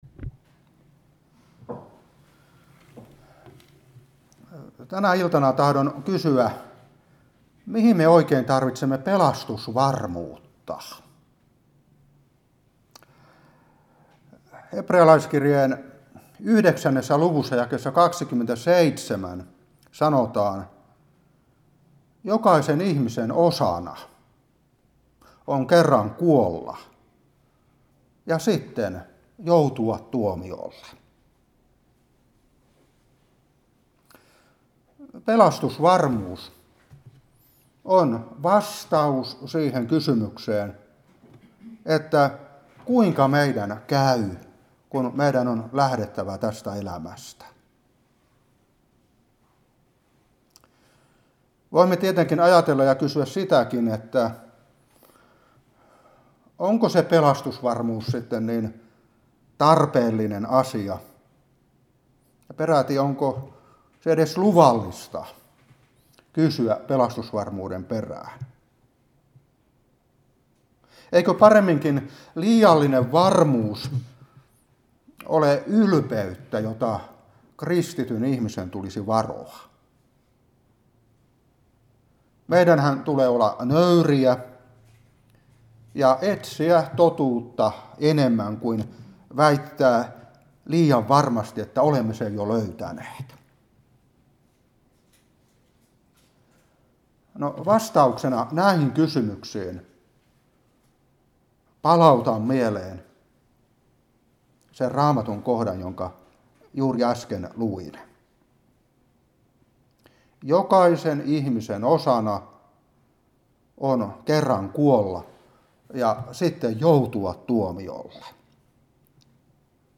Opetuspuhe 2023-9. Hepr.9:27. Ef.1:7. 1.Tess.1:5. 1.Tim.1:15. 1.Kor.10:12. Room.11.19-20.